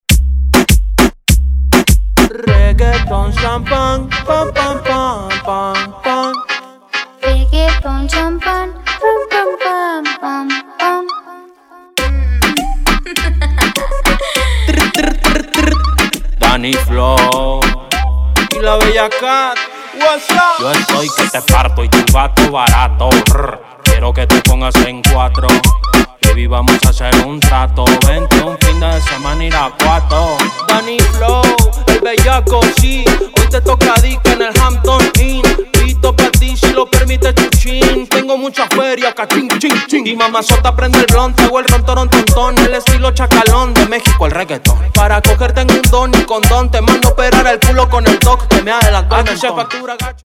Dive into the pulsating rhythms and energy of Latin music